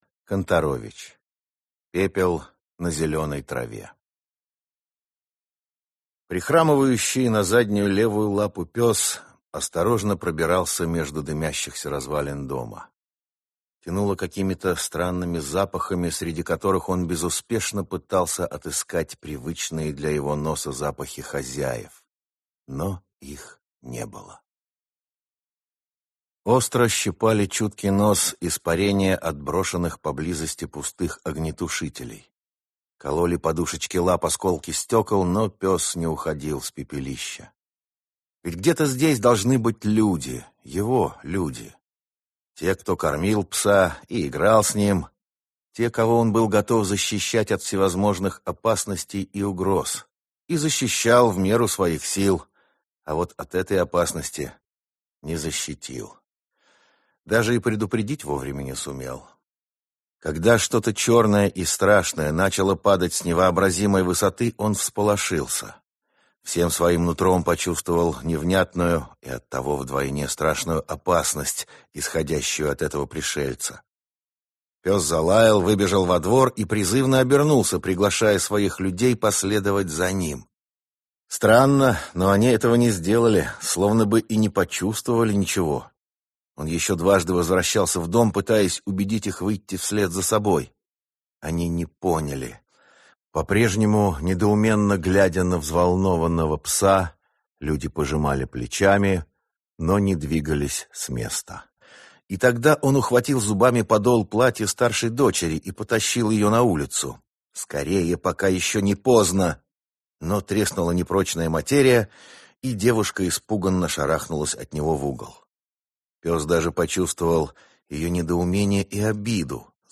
Аудиокнига Пепел на зеленой траве | Библиотека аудиокниг